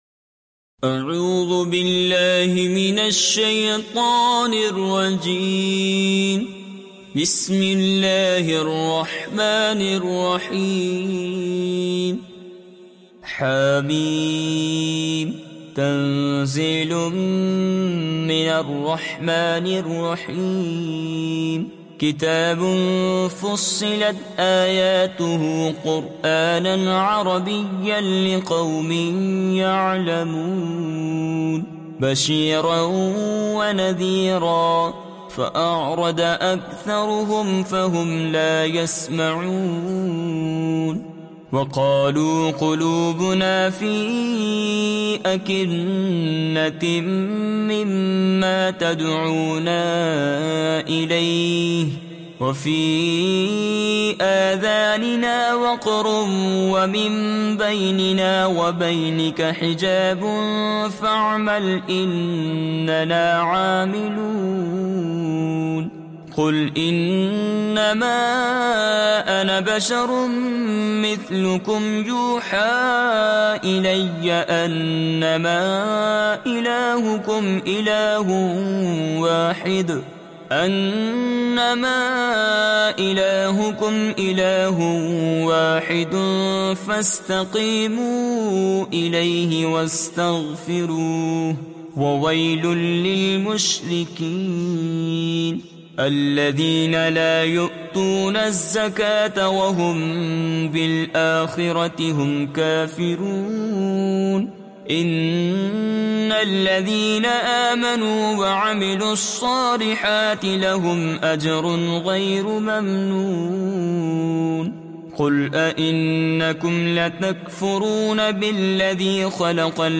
From this page, you can read Surah Fussilat online listen to its mp3 audio, download recitations, and download a PDF to read it offline with Urdu and English translations for better understanding.